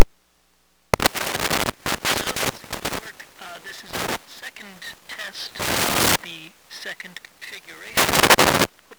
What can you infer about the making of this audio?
I am able to get sound out of the thing though a positive bias, but it remains a deeply unplesant scratch against a very faint signal. 1. Carbon filament audio test 2. Carbon filament audio test with a 4.7uf filter capacitor, no use electret-circuit.wav